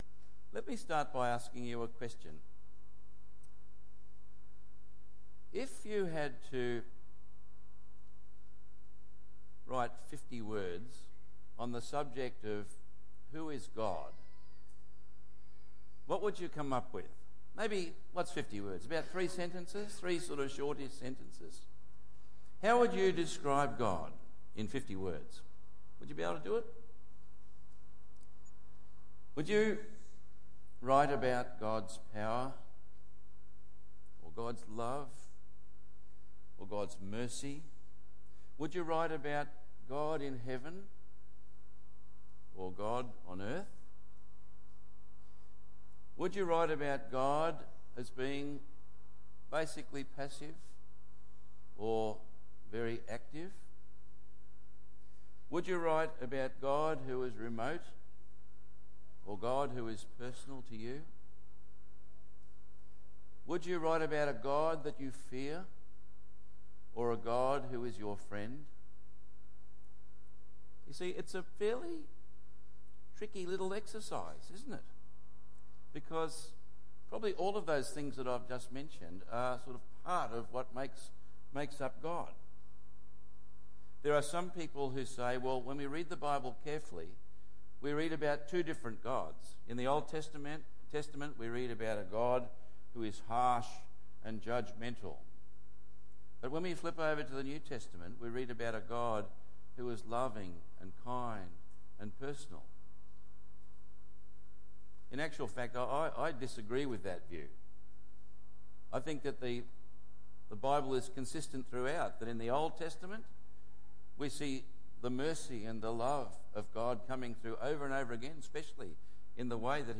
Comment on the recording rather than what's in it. He is GOD in the fullest sense and always deserves our worship, awe and fear. 1 Samuel 5:1-12 Tagged with Sunday Morning